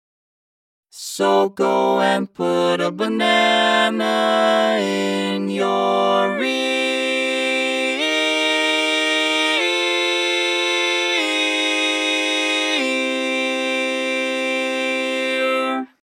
Key written in: C Major
How many parts: 4
Type: Female Barbershop (incl. SAI, HI, etc)
All Parts mix: